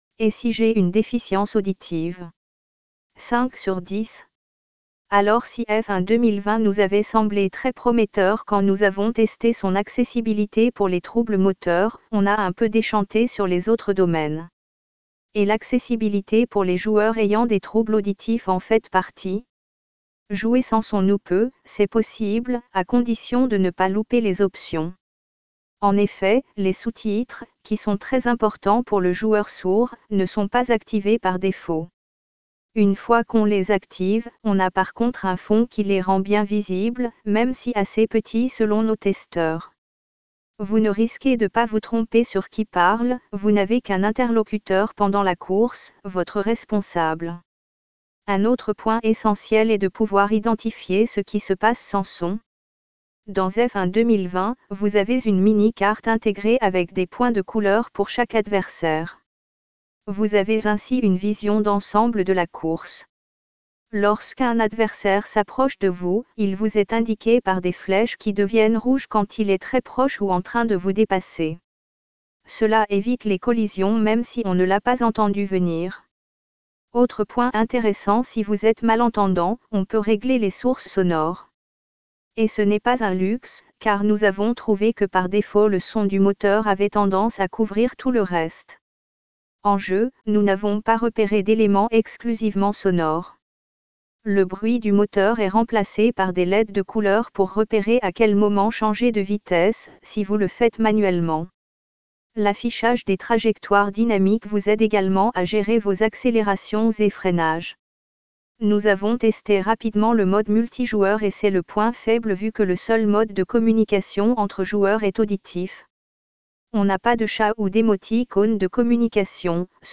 un avis d’expert